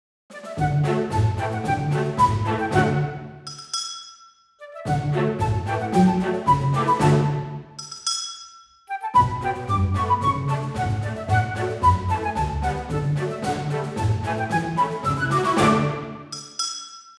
A fun little march.8 bars at 112 bpm ends on 17:14.Double bass, violas, flute snare and anvil.D major.